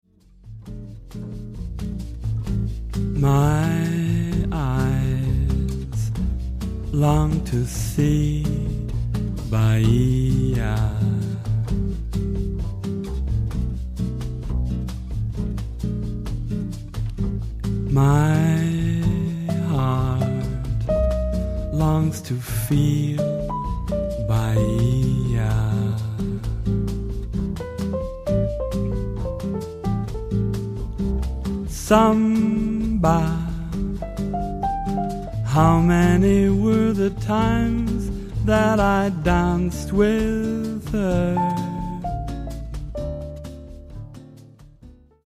飾らないサウンドとなでるような歌声に気持ちをほだされる、粋で洒落たボッサ＆ジャジーな夜に、あなたは何を想う…？